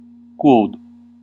Ääntäminen
US : IPA : [bɪ.ˈkʌz] UK : IPA : /bɪ.ˈkɒz/ US : IPA : /biˈkɔz/ IPA : /biˈkʌz/